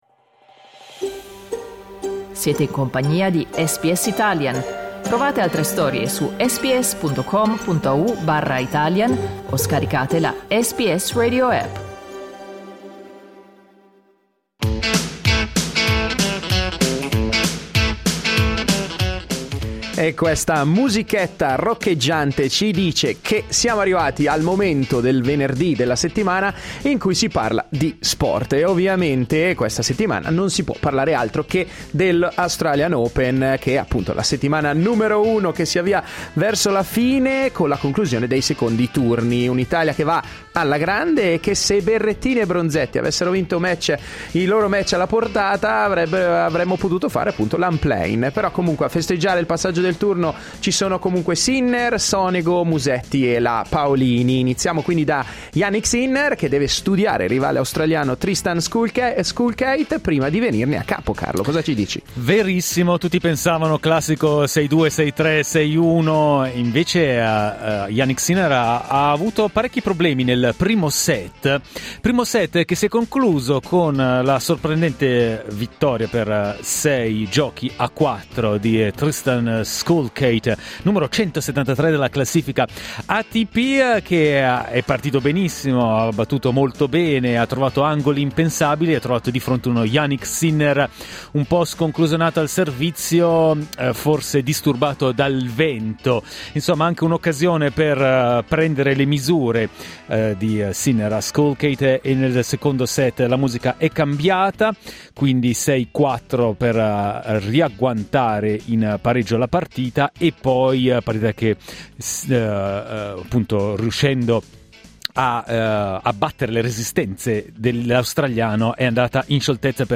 Clicca sul tasto "play" in alto per ascoltare le voci dei protagonisti degli Australian Open 2025 Matteo Berrettini e Lucia Bronzetti invece escono dal torneo di quest'anno, in due incontri che avrebbero potuto girare a loro favore.